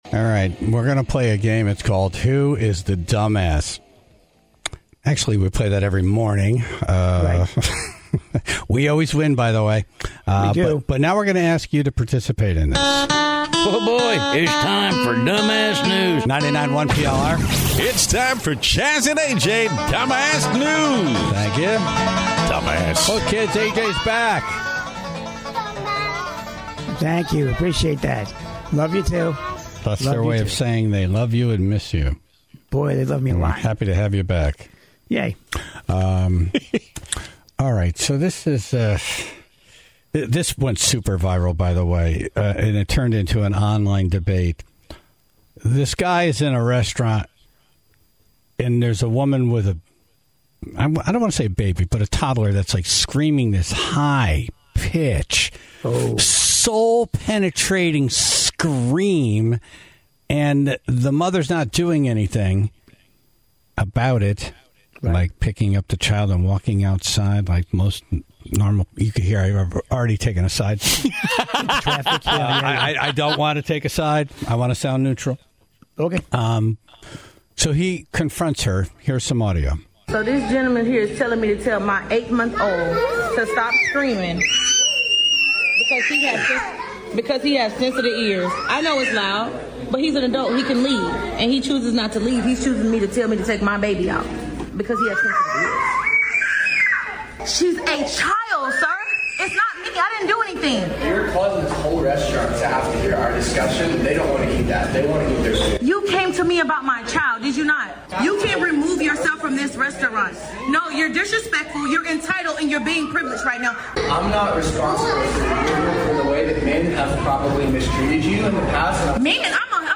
(0:00) The screaming kid debate continues, as the Tribe called in their stories of encounters with horrible people.